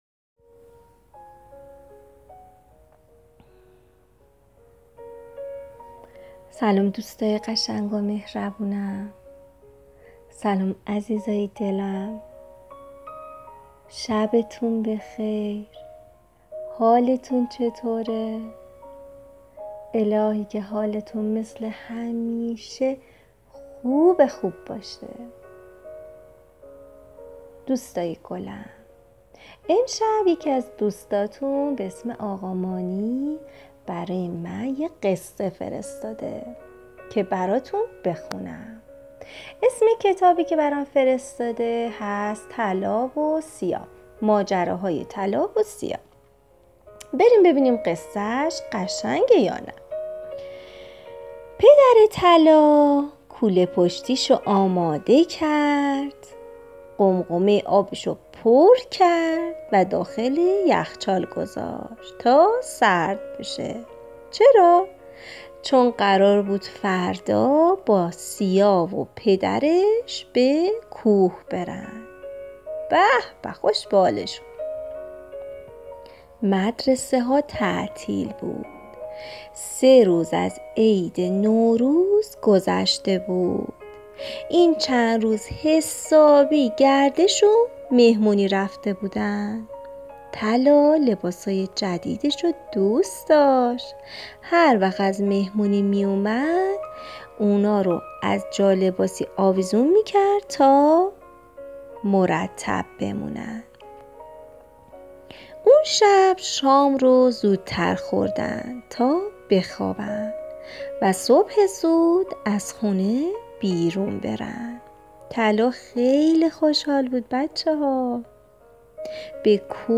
قصه صوتی کودکان دیدگاه شما 912 بازدید